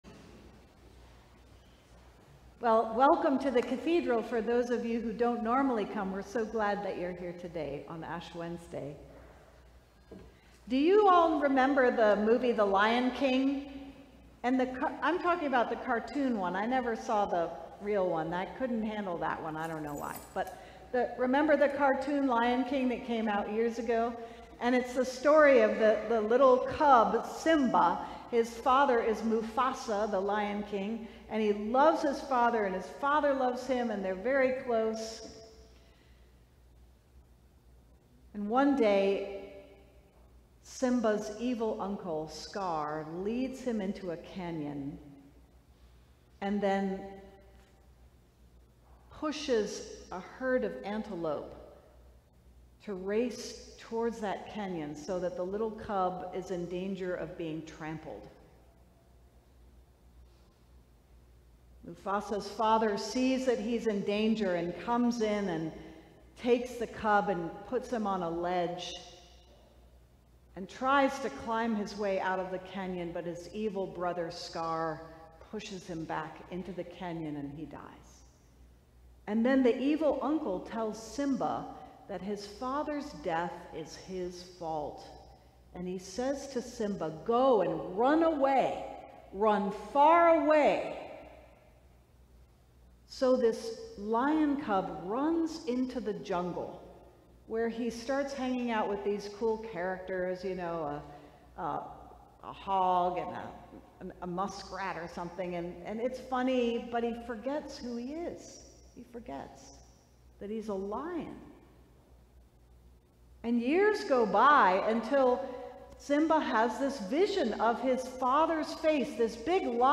Ash Wednesday Sermon: The Lion King
Sermons from St. John's Cathedral